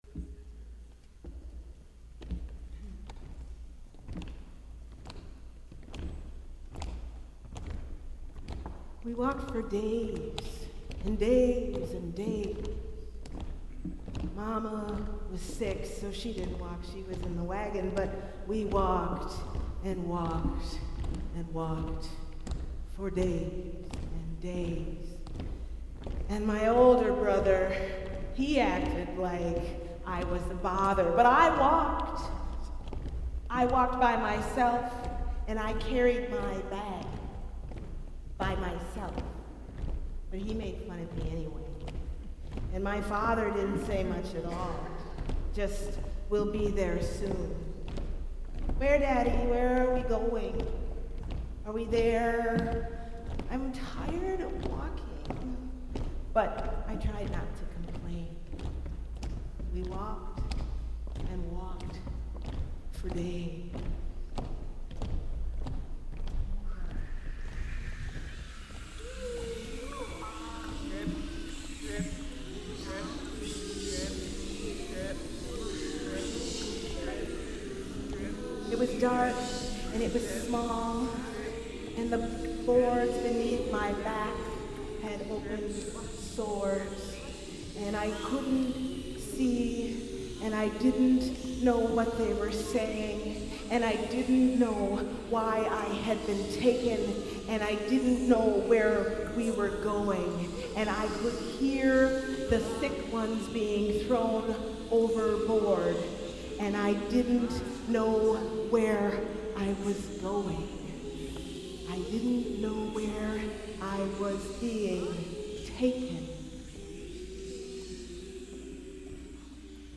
The quote above pretty much sums up my whole experience of composing and performing my improvised piece with the Echoes of Peace choir last weekend.